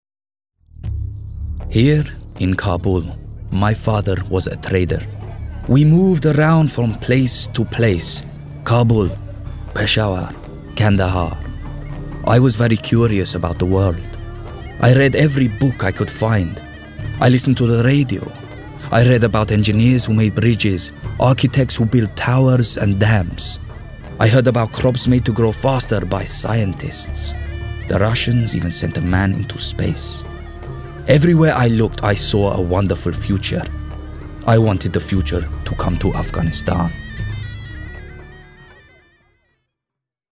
LONDON / STREET / RP / ASIAN.
His voice range is late teens through to 30s, and deliveries from London urban to RP, as well as conversational Punjabi and some Middle & Far Eastern accented-English.